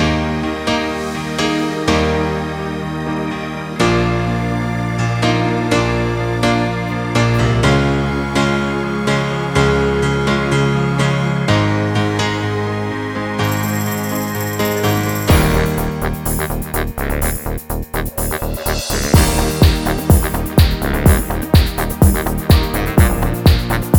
Minus Main Guitars Pop (1990s) 3:50 Buy £1.50